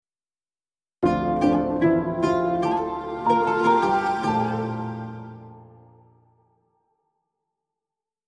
Audio Branding Elements
Audio Logo